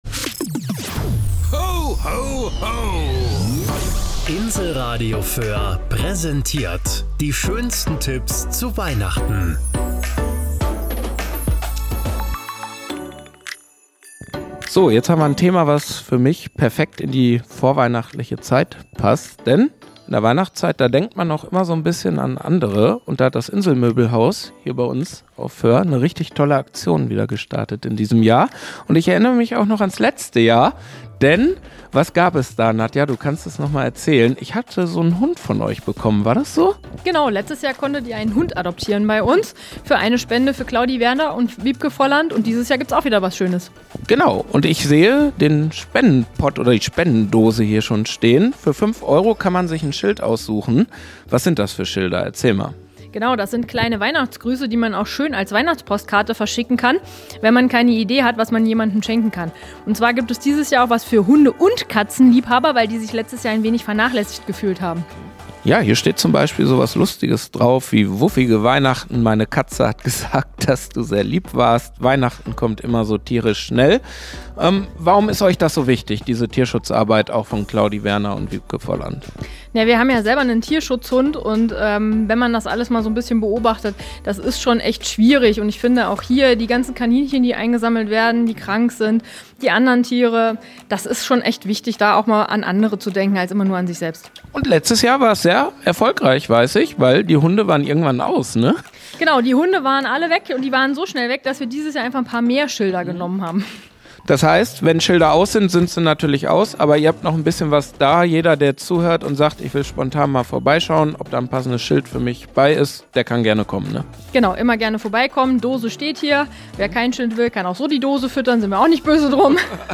Beitrag-Schilder-fue-den-Tierschutz-im-Inselmoebelhaus.mp3